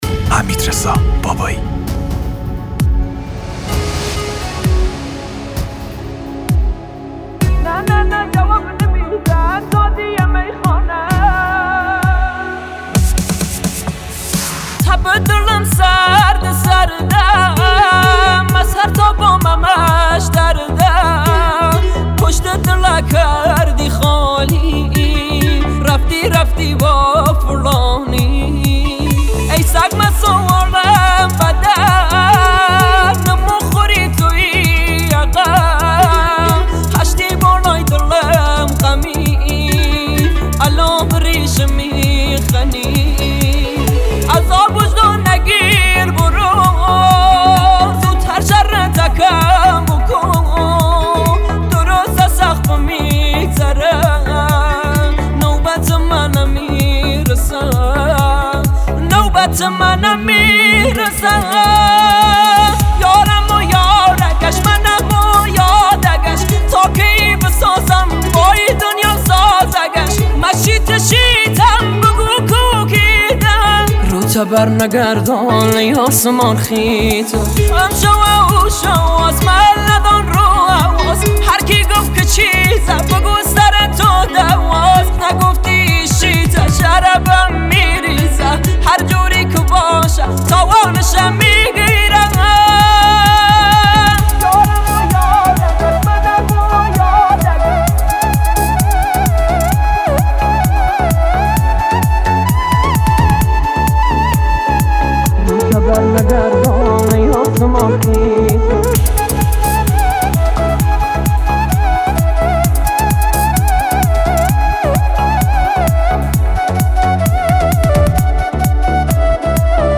با صدای گرم